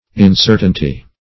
Incertainty \In*cer"tain*ty\, n.